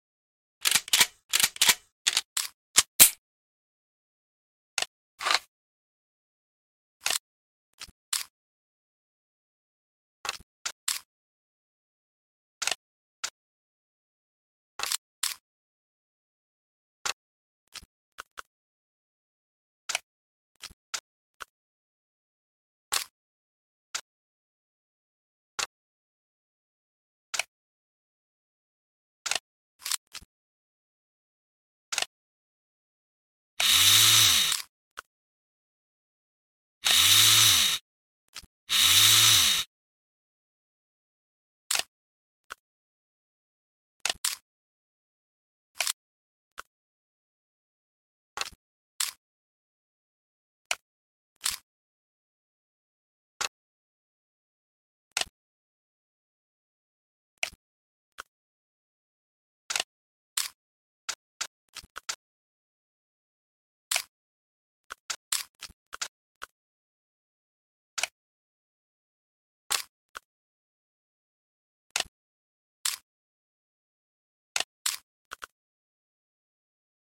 B&T MP9 Full disassembly sound effects free download
B&T MP9 - Full disassembly Mp3 Sound Effect